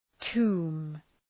Προφορά
{tu:m}